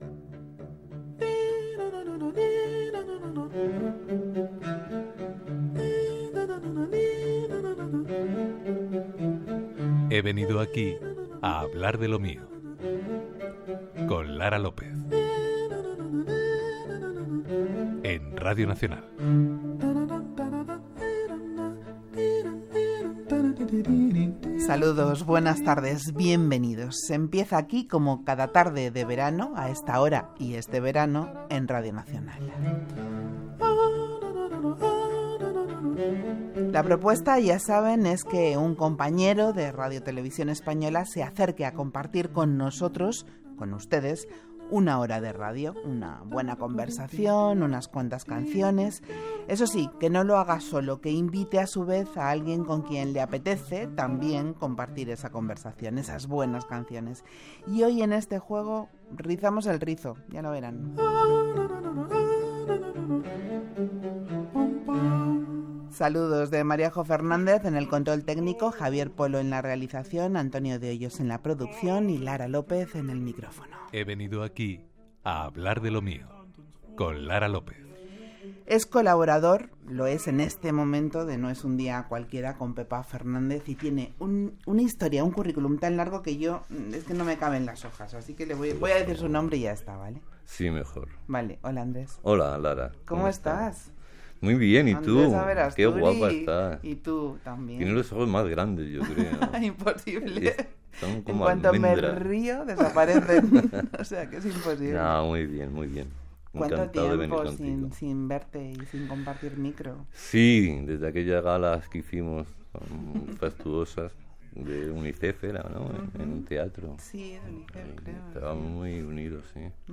Careta del programa, presentació, equip, indicatiu
Gènere radiofònic Entreteniment